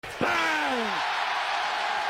bang basketball Meme Sound Effect
bang basketball.mp3